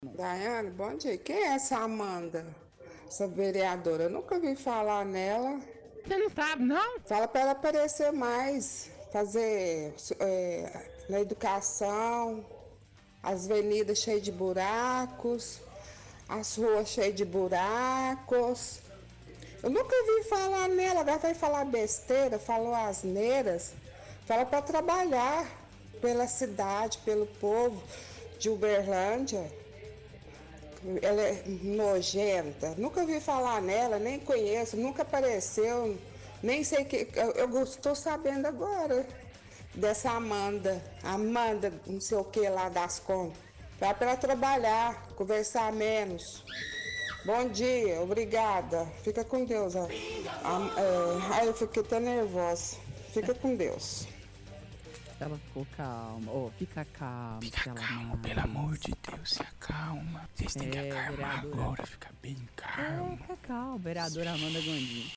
– Ouvinte reclama da fala da vereadora Cláudia Guerra, dizendo que nunca ouviu falar nela, e pedindo para quela ela trabalhe mais, cuidando dos buracos da cidade.